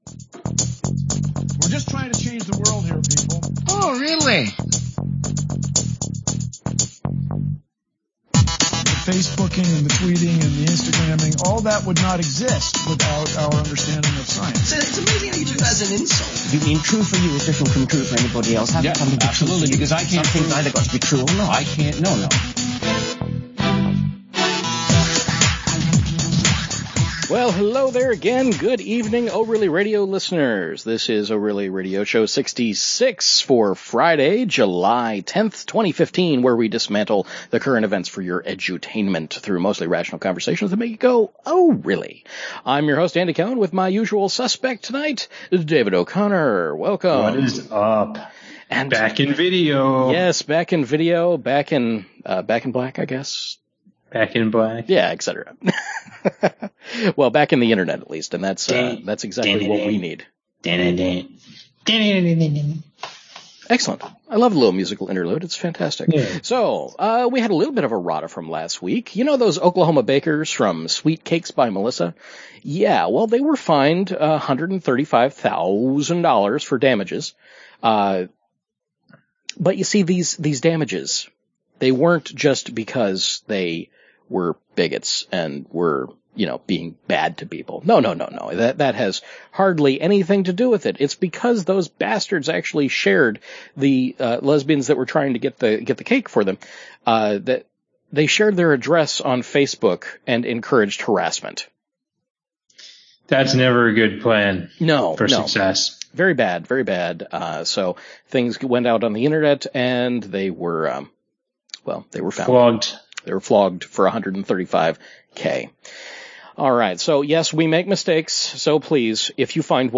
Live every Friday night at about 9pm